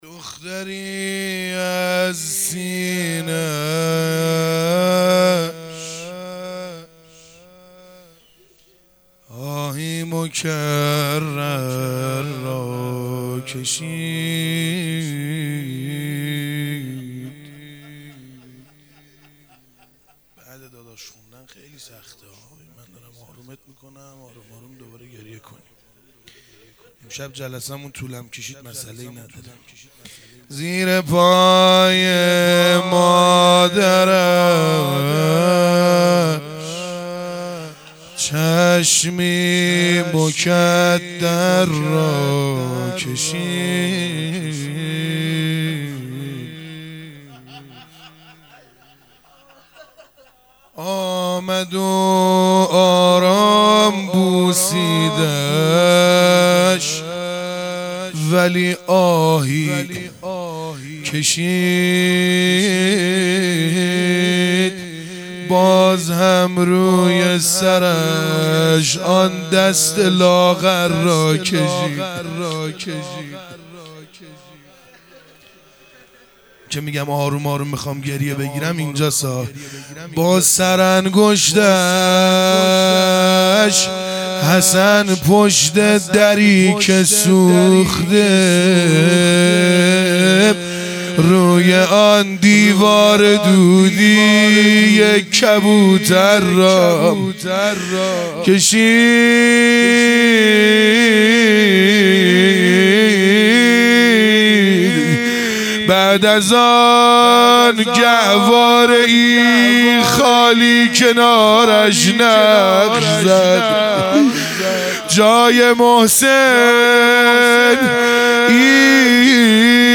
دهه دوم فاطمیه | شب سوم | روضه